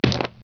splats1h.wav